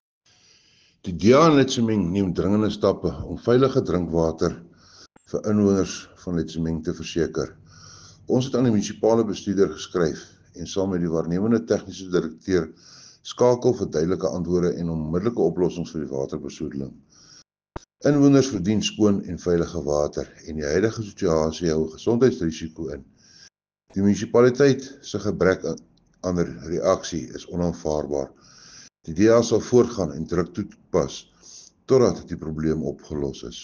Afrikaans soundbite by Cllr Johann Steenkamp, and